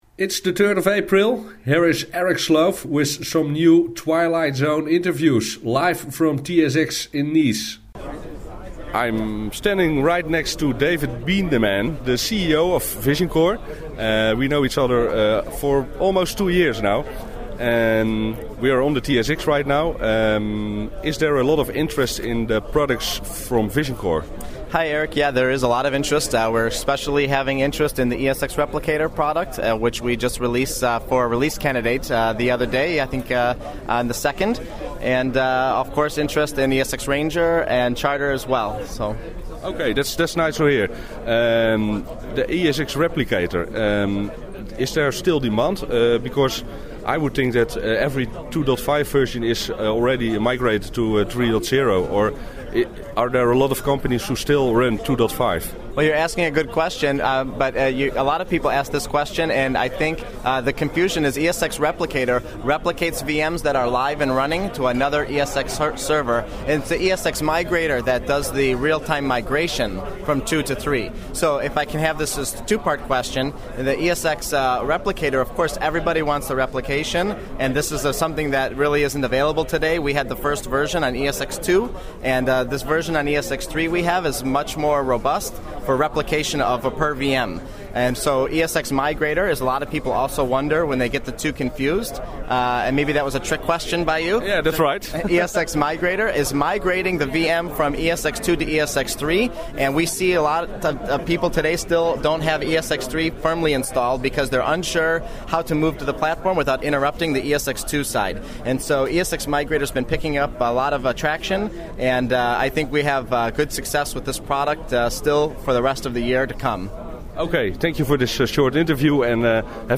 TSX Interview